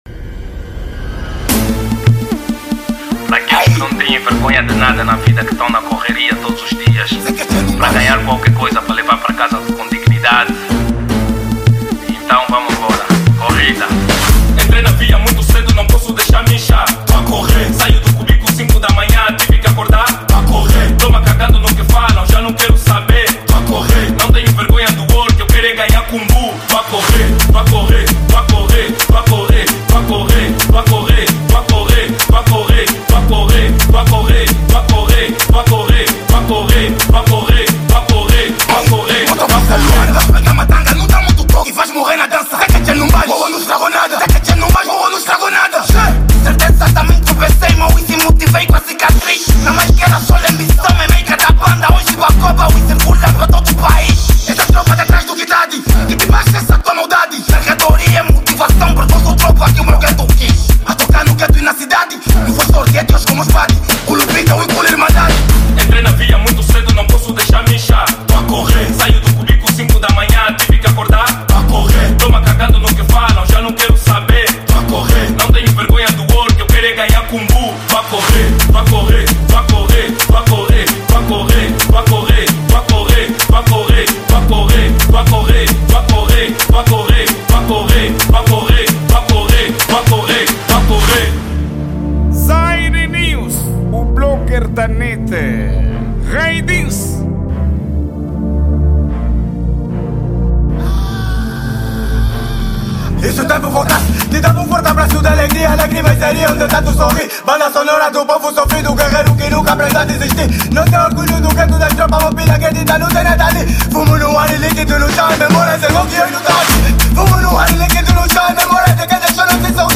Género:Rap